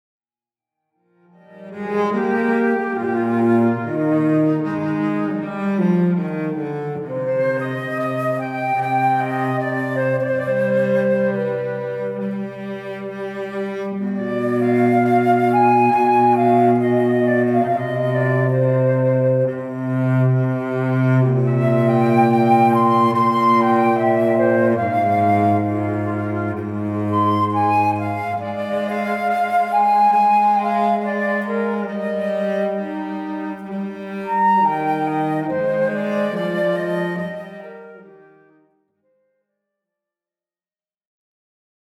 en ut mineur-Allegro